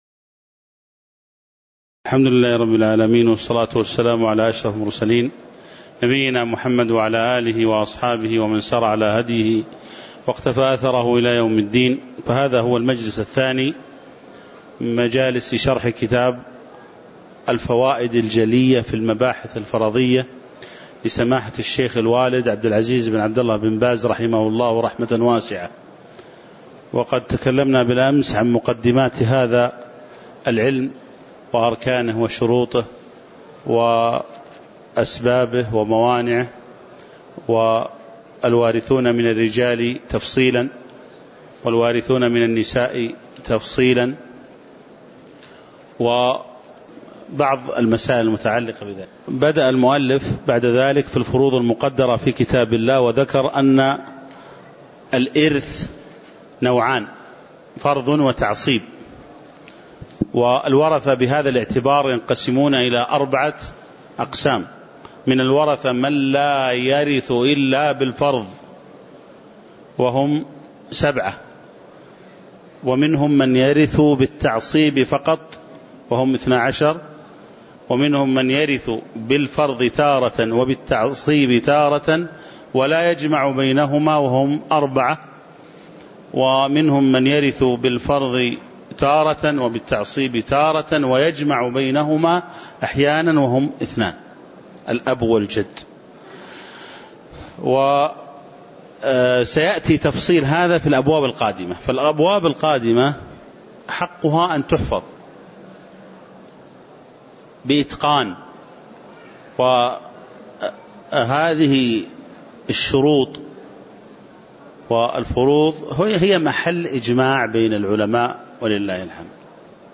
تاريخ النشر ١ جمادى الأولى ١٤٣٩ هـ المكان: المسجد النبوي الشيخ